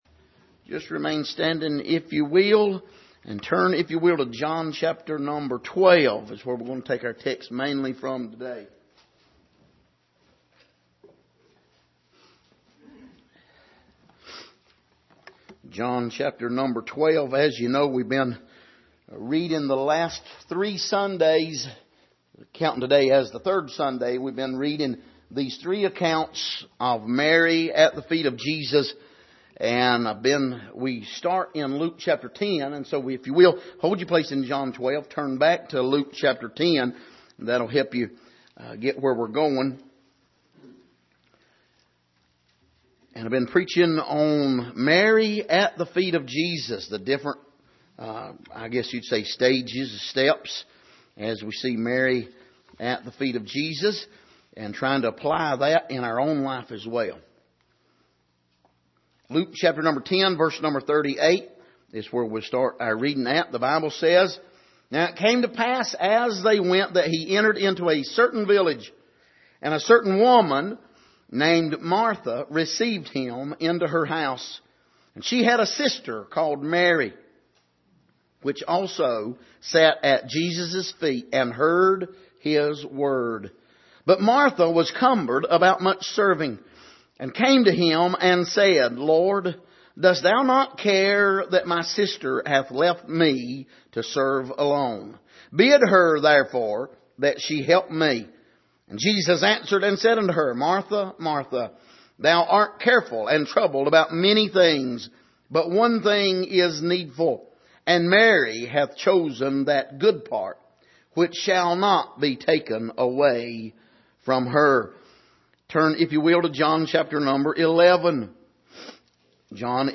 Passage: Luke 10:38-42 Service: Sunday Morning